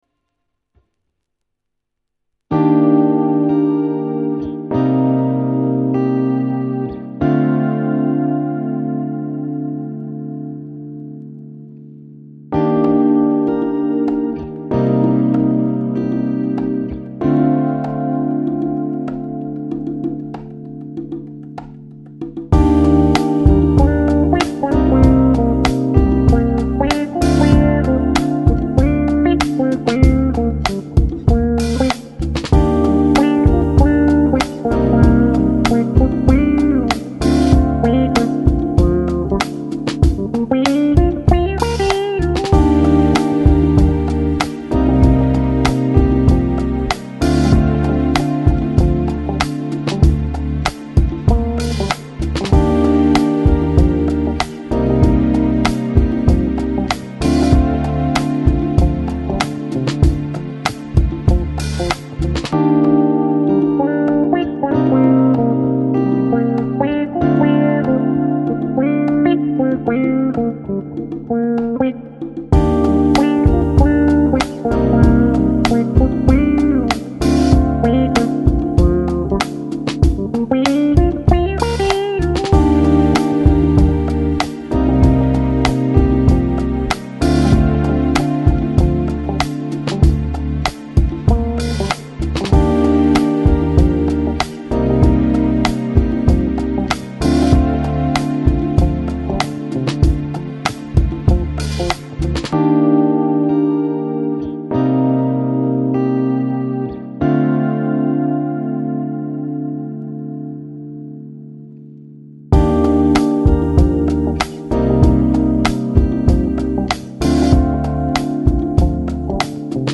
Жанр: Chill Out, Smooth Jazz, Downtempo